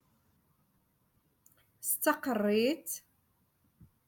Moroccan Dialect- Rotation Three- Lesson Seventeen